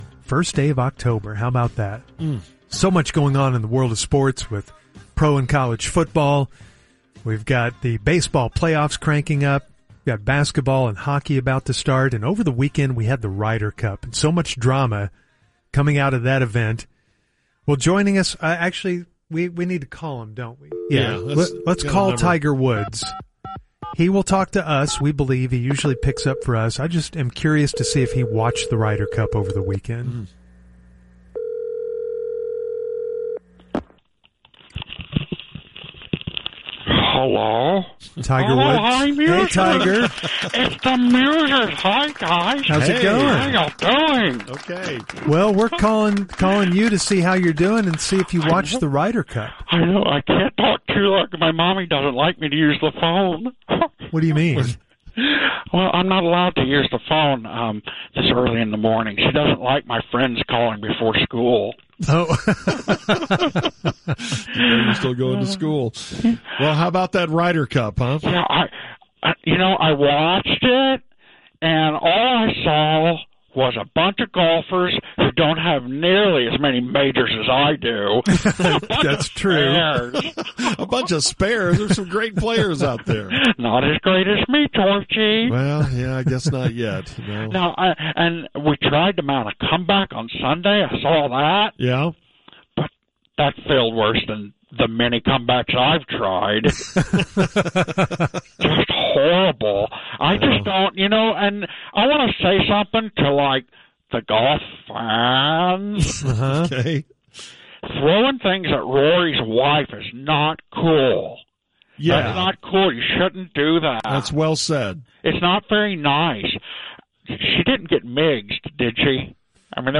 Fake Tiger – The Musers 10.1.2025